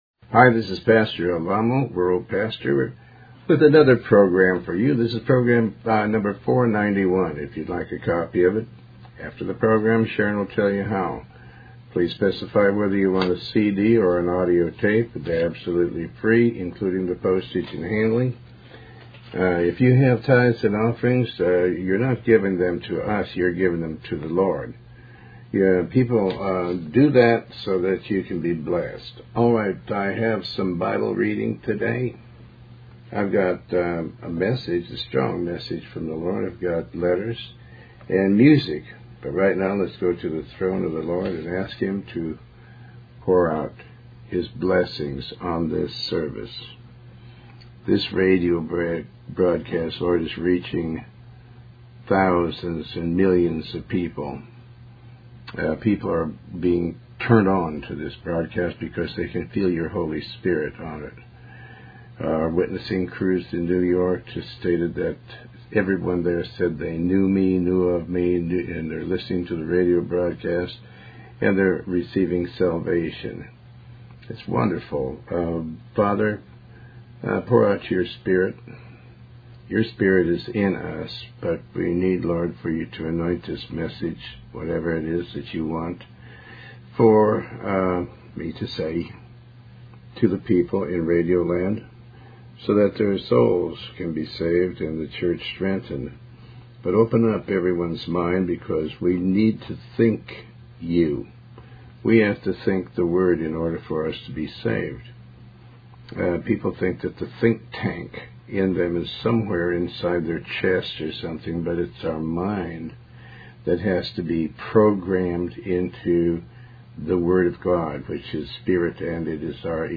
Tony Alamo Talk Show